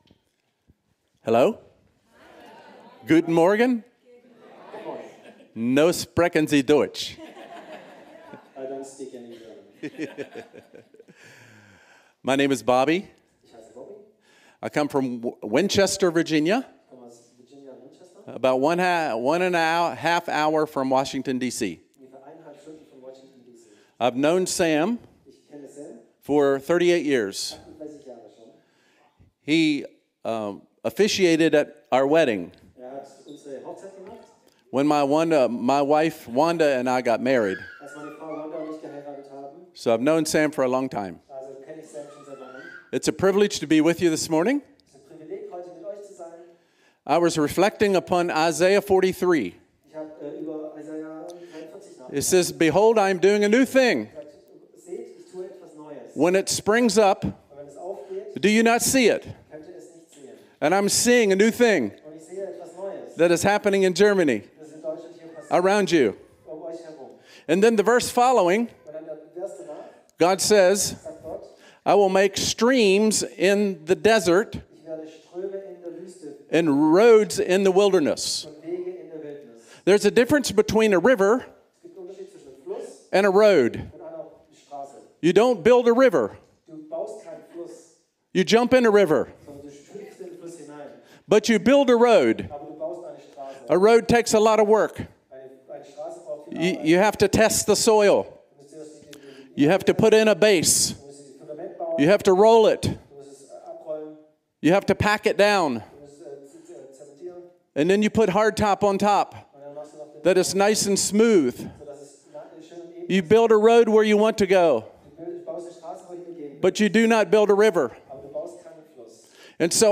Predigten der Ekklesia